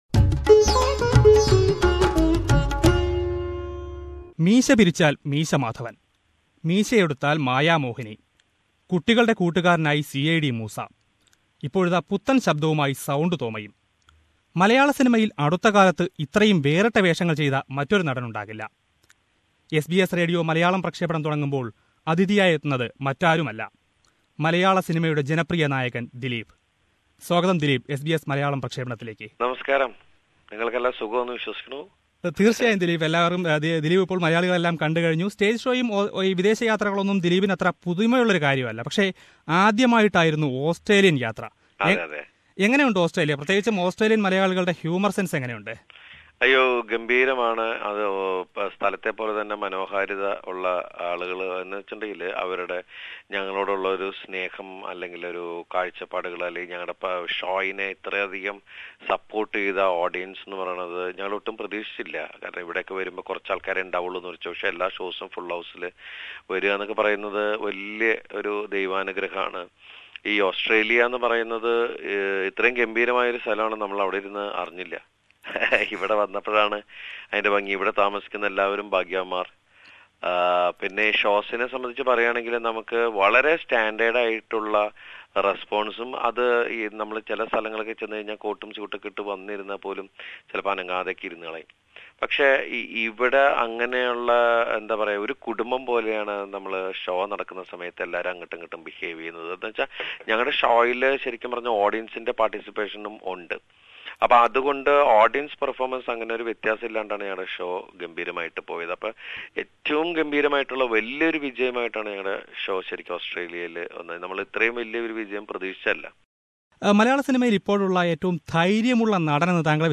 Famous film star Dileep talks about his recent Australian visit and experience in the country, in an exclusive interview to SBS Malayalam Radio